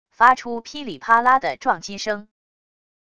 发出噼里啪啦的撞击声wav音频